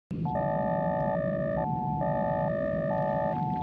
BSG FX - Attack claxon bell
BSG_FX_-_Attack_claxon_bell.wav